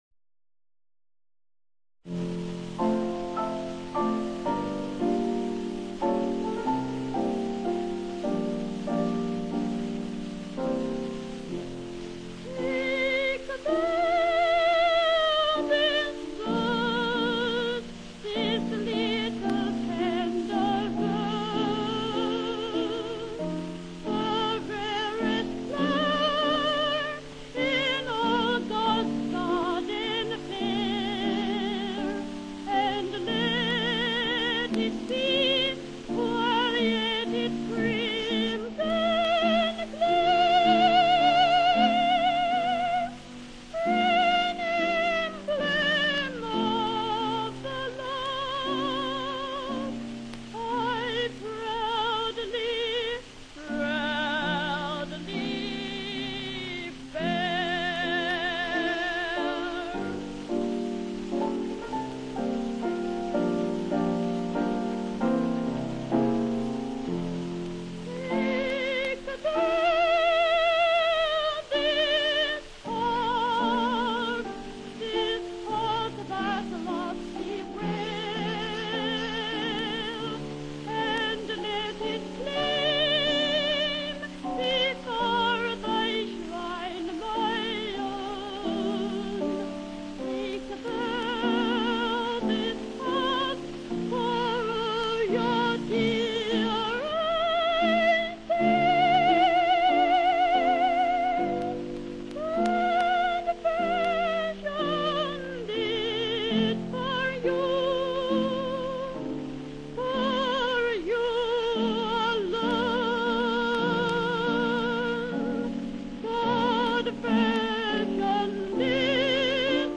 Great Boy Sopranos of the Early Twentieth Century